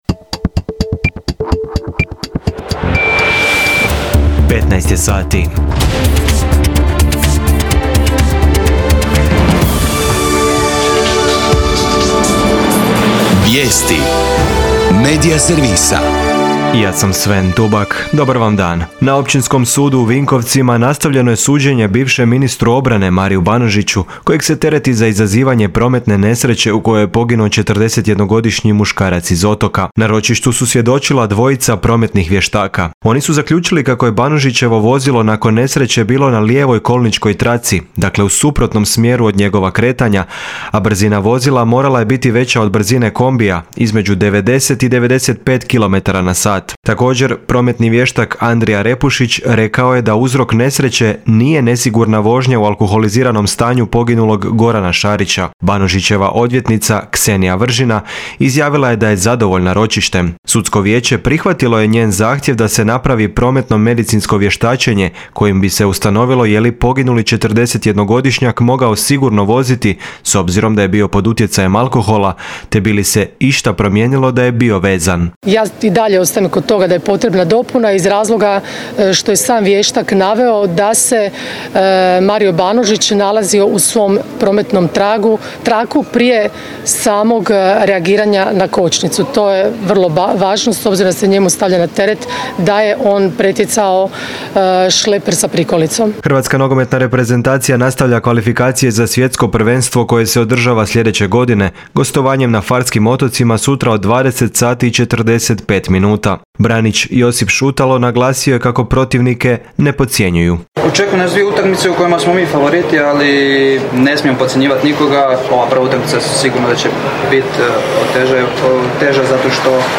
VIJESTI U 15